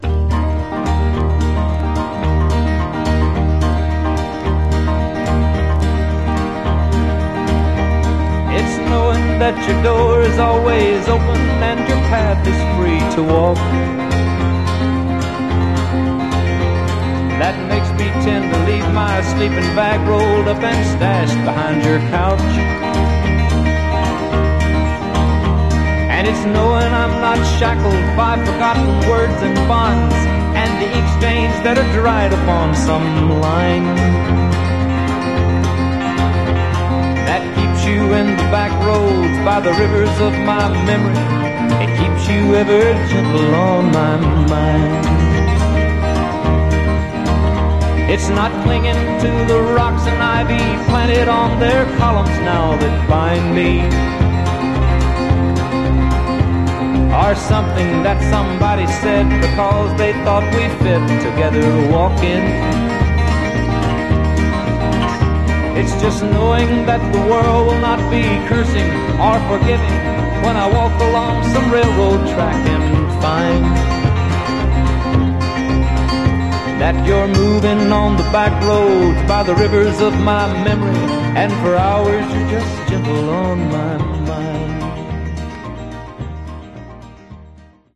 Genre: Country Rock